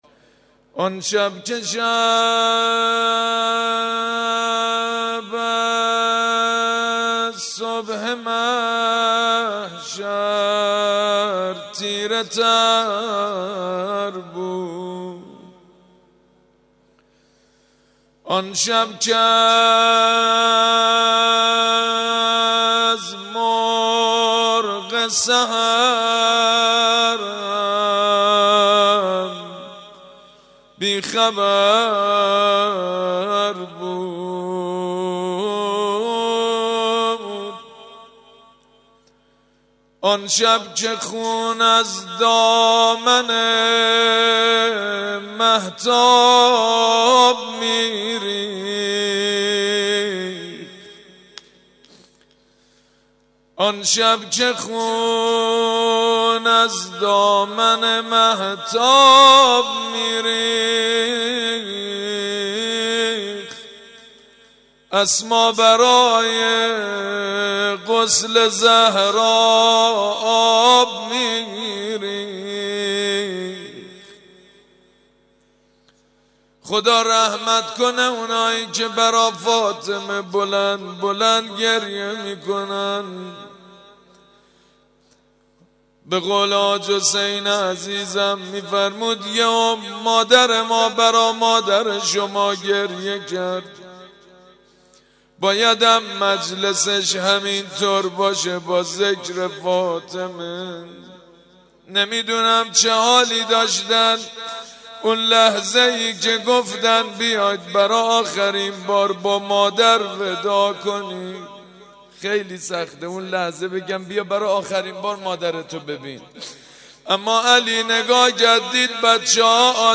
عقیق: در این مراسم تعدادی از ذاکرین اهل بیت(ع) مداحی کردند که در ادامه صوت مداحی ها منتشر می شود:
مداحی
در مراسم ترحیم